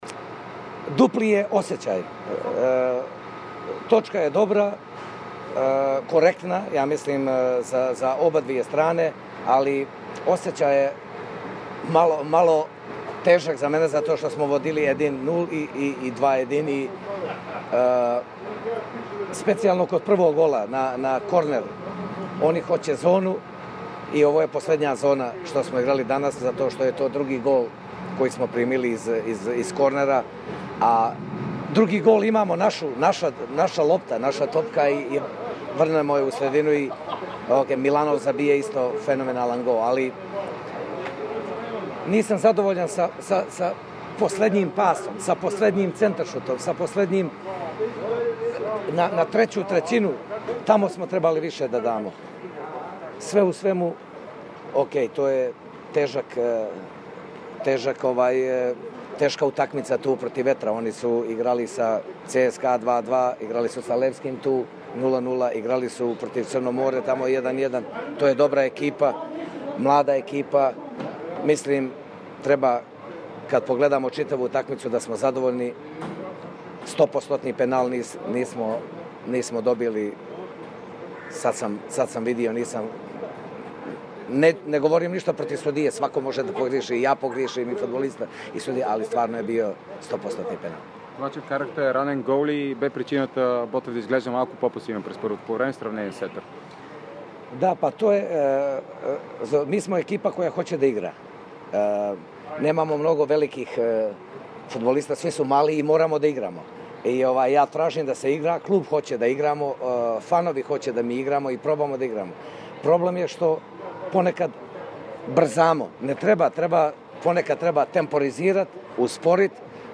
Треньорът на "канарчетата" Желко Петрович пробва да коментира мача на отбора с Етър на български!